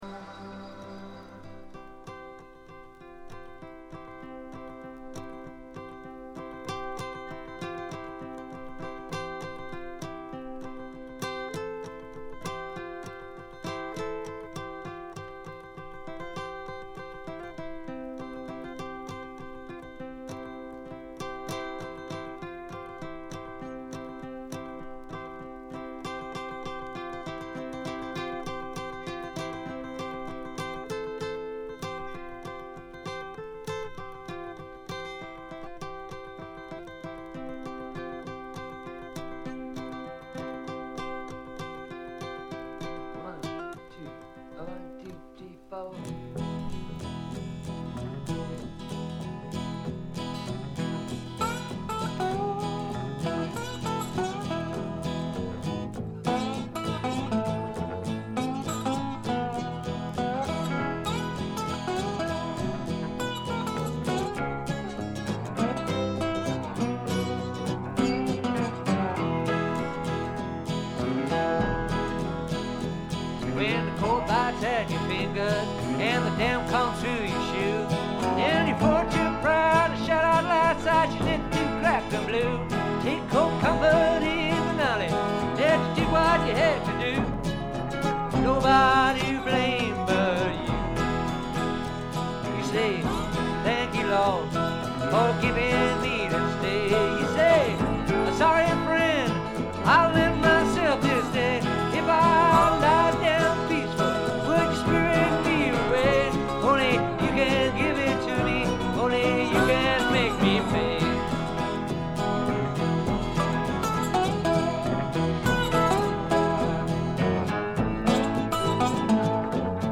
ほとんどノイズ感無し。
試聴曲は現品からの取り込み音源です。
Mixed at IBC.